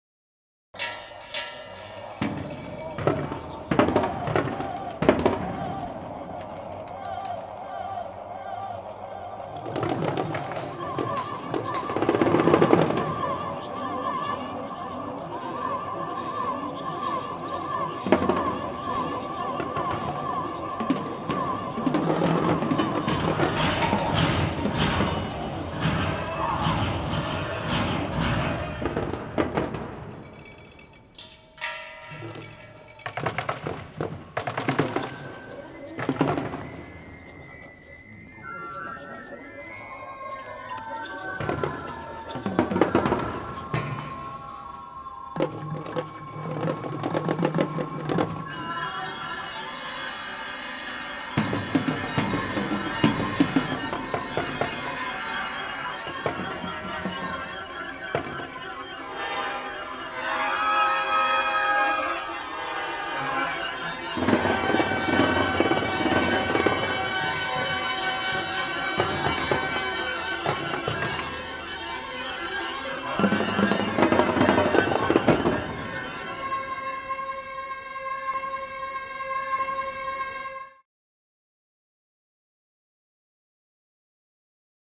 in the stone quarry of Matera, Italy.